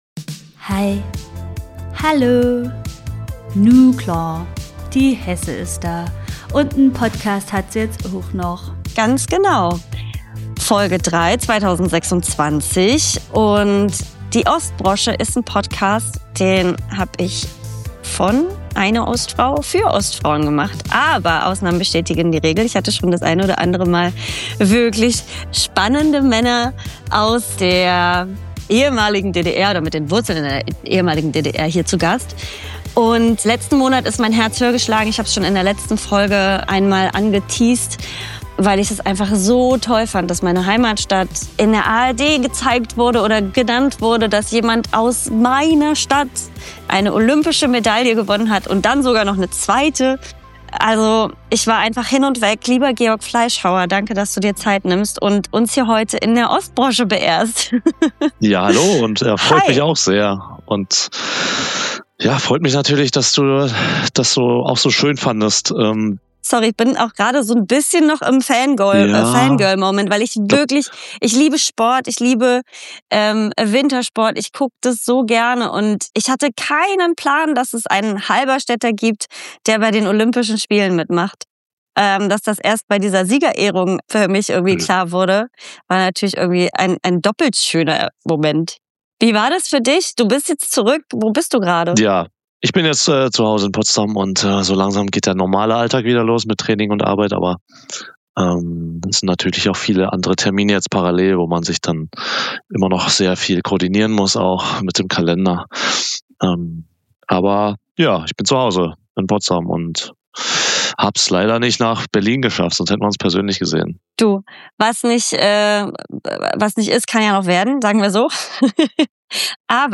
#34 Ein Gespräch mit Georg Fleischhauer, Doppel-Olympiasieger 2026, Teamplayer & loyal bis zum Happy End.